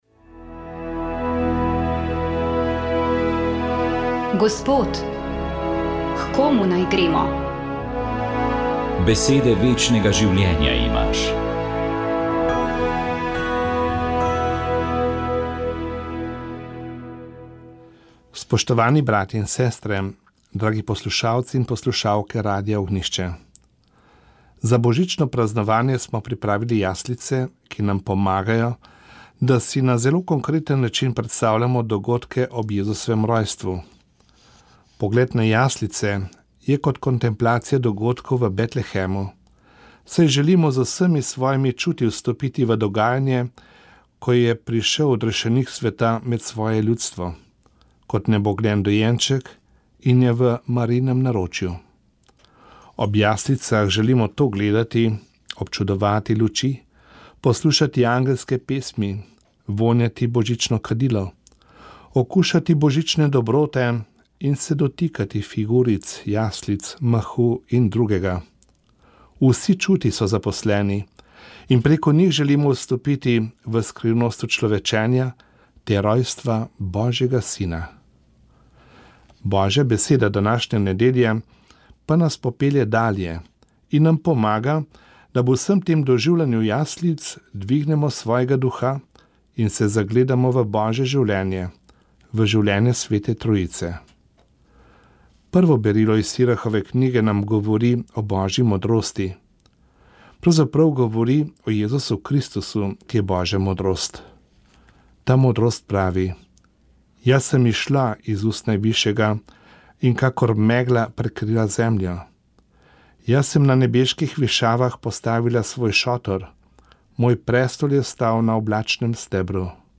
Konferenca združuje ljubitelje astronomije, strokovnjake in proizvajalce opreme za opazovanje zvezd, z namenom opazovanja nočnega neba. Pogovarjali smo se o astrofotografski poti našega gosta in opozorili na prihajajoči zimski meteorski roj Geminidi.